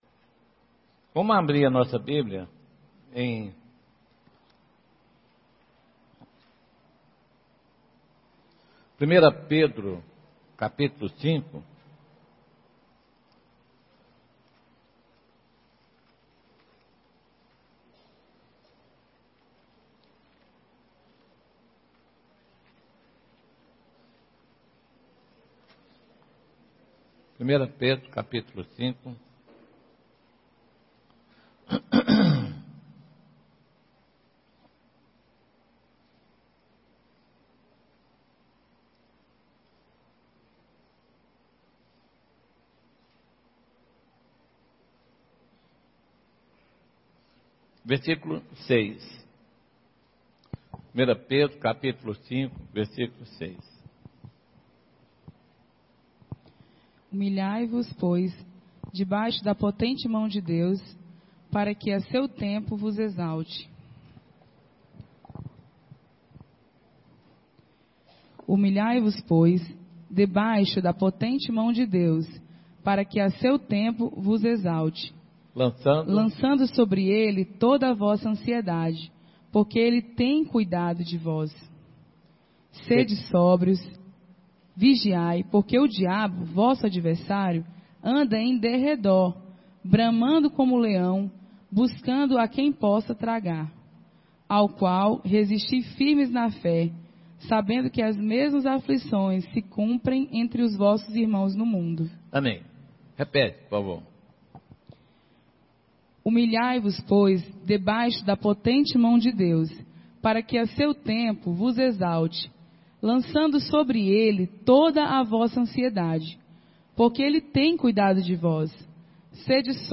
Encontro de Jovens 2016 – 3 – Vol VII